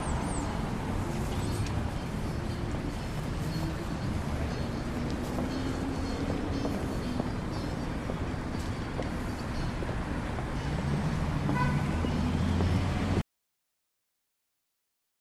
sound art
Soundscapes
Noise music